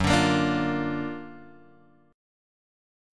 F# Augmented Major 7th